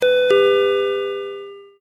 ConvenienceStoreRing
Convenience-Store Ding Ding-Dong Door-Bell Door-Ding-Dong Door-Ring Ring Store sound effect free sound royalty free Sound Effects